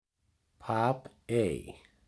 Papey (Icelandic pronunciation: [ˈpʰaːpˌeiː]
Papey_pronunciation.ogg.mp3